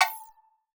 Game Notification 2.wav